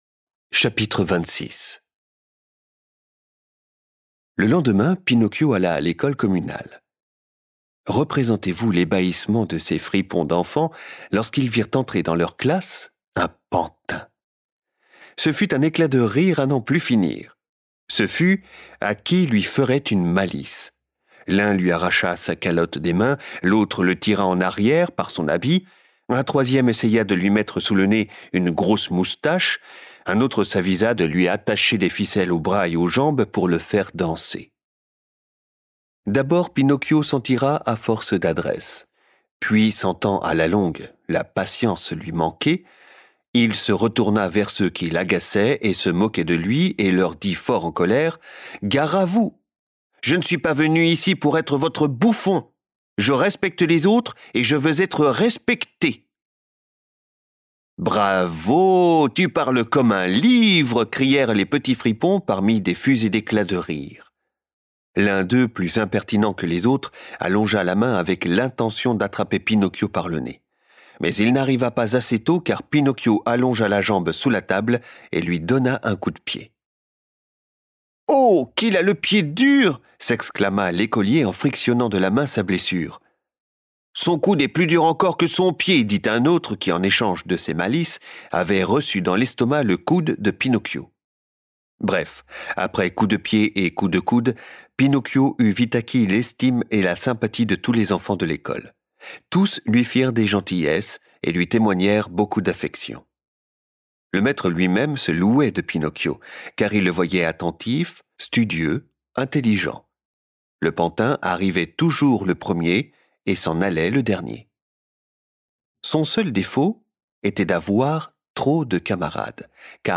Envie de découvrir de la littérature audio en famille ?